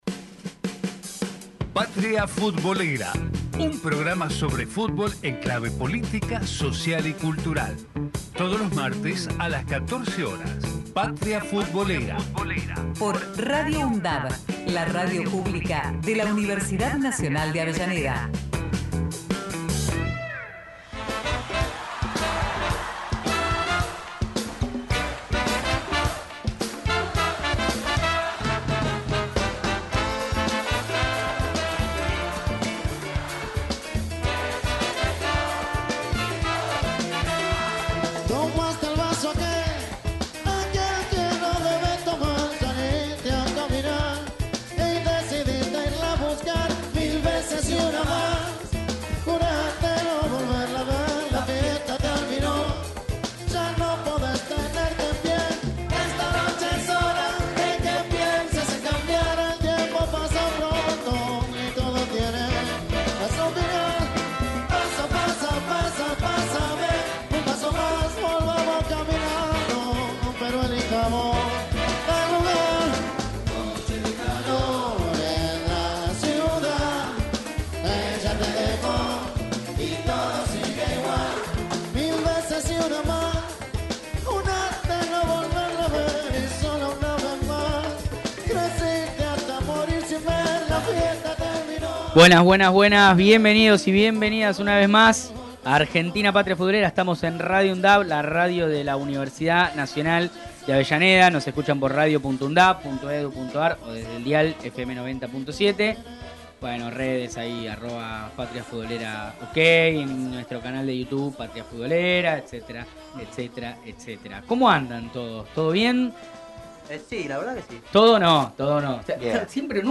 Patria Futbolera Texto de la nota: Un programa sobre fútbol en clave política, social y cultural. Realizado en la radio de la Universidad Nacional de Avellaneda